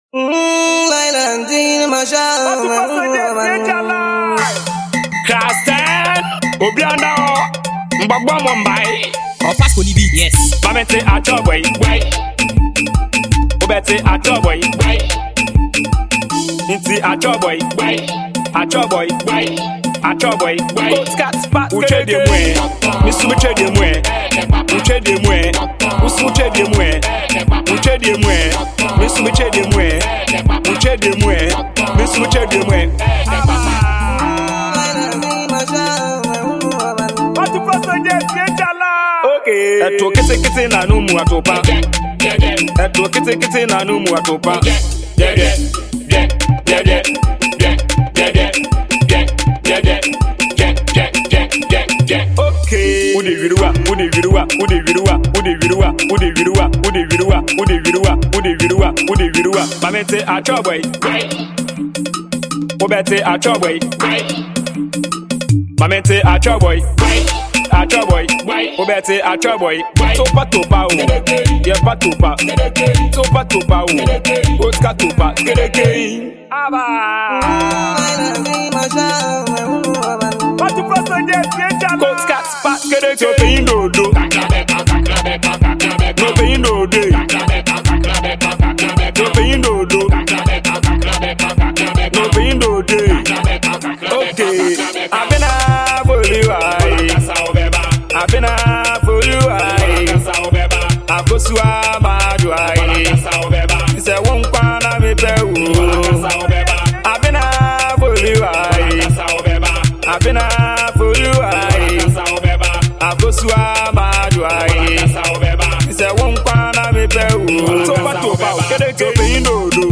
party song